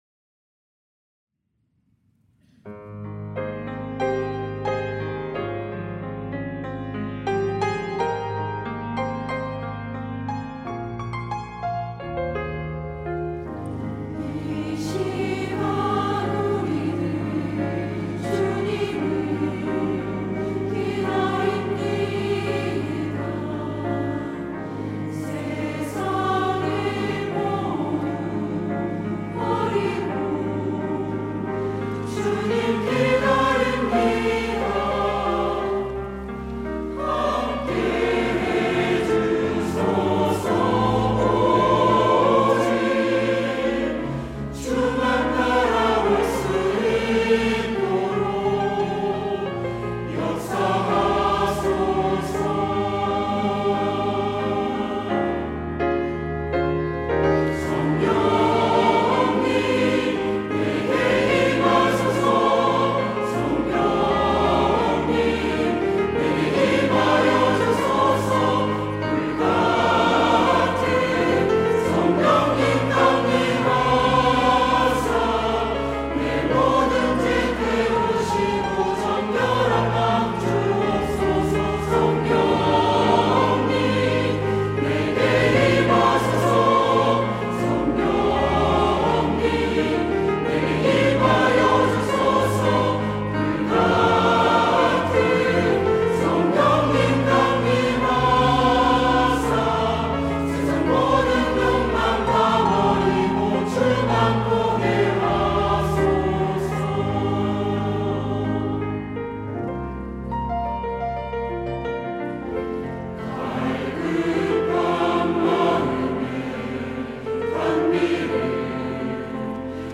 시온(주일1부) - 성령님 내게 임하소서
찬양대